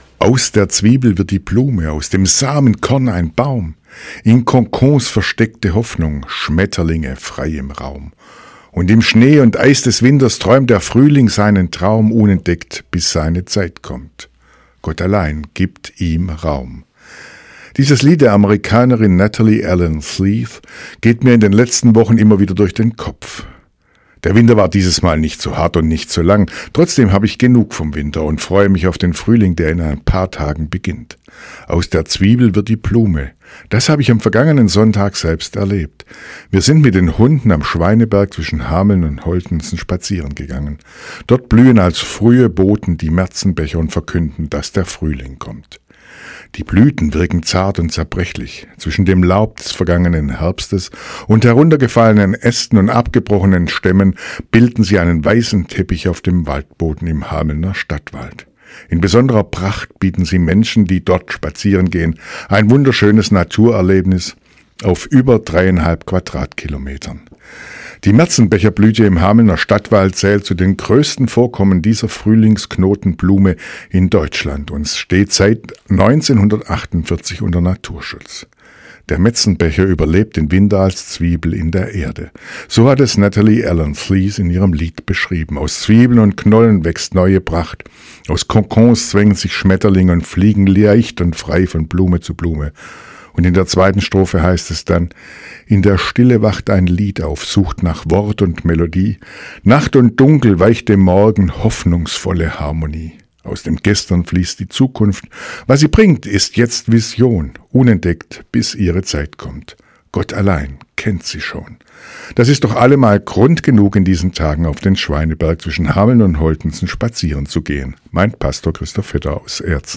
Radioandacht vom 18. März